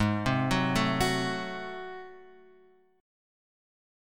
G#7b9 chord {4 3 4 2 x 2} chord